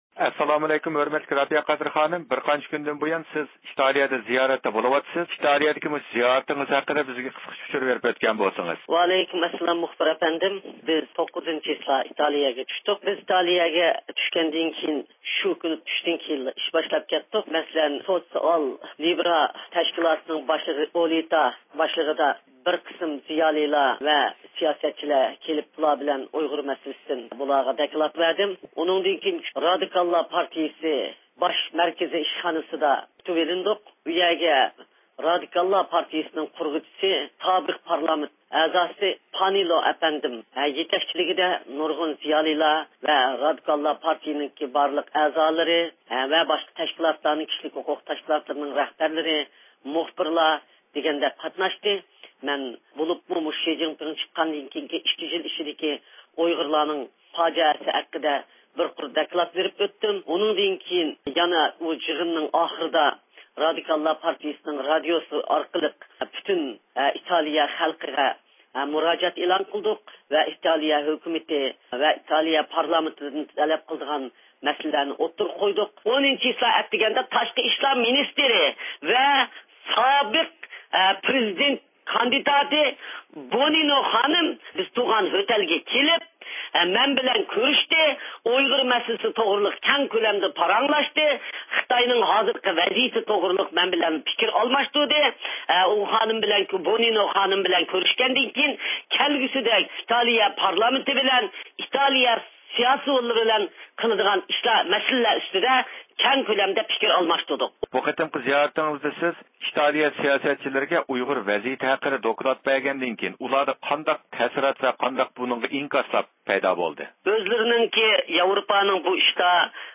بىز رابىيە قادىر خانىمنىڭ ئىتالىيە سەپىرى ھەققىدە تولۇق مەلۇماتقا ئېرىشىش ئۈچۈن ئۇنىڭ بىلەن تېلېفون سۆھبىتى ئېلىپ باردۇق.